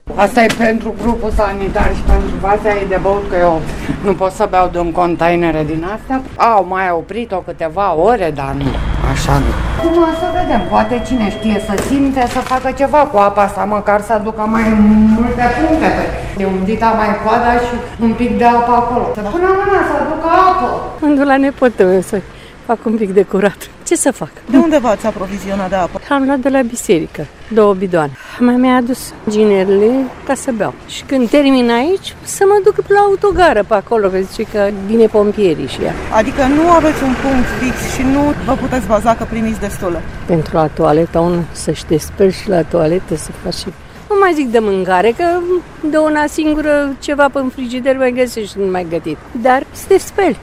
Ce spun locuitorii din Câmpina despre problema apei:
insert-vox-03-12-apa.wav